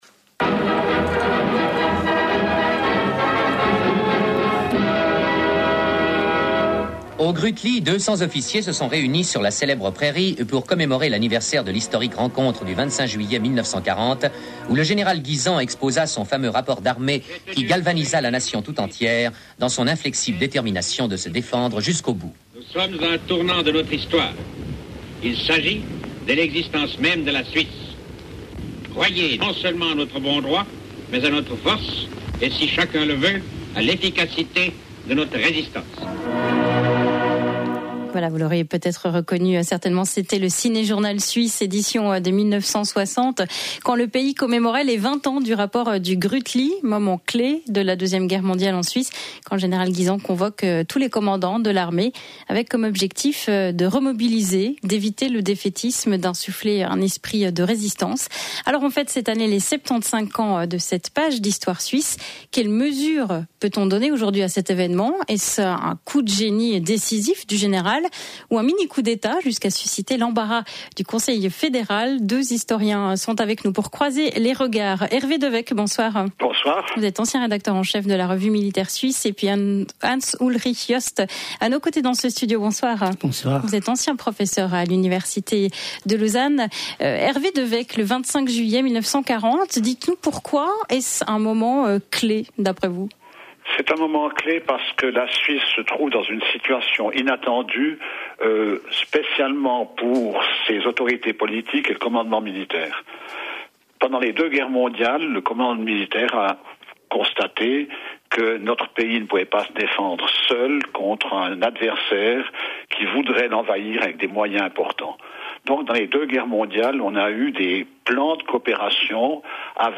Coup de génie ou mini coup d’Etat? Le débat entre les historiens